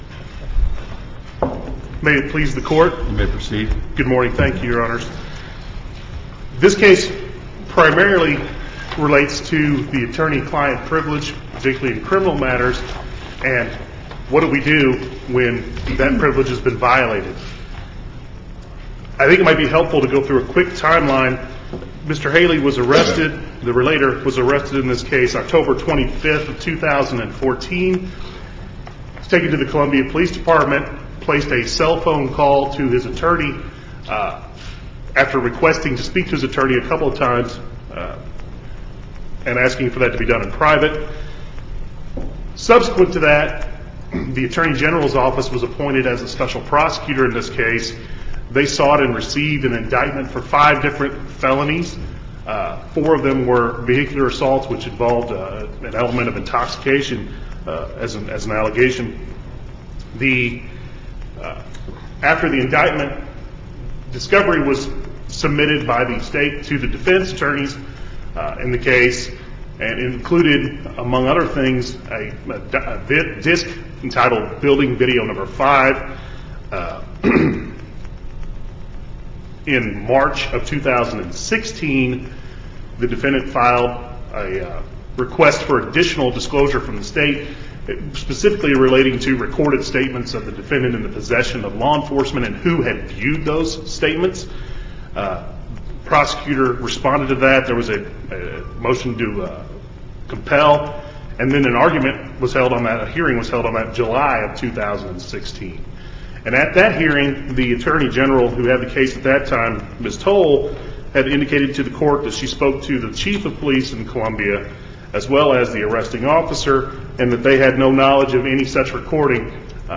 oral argument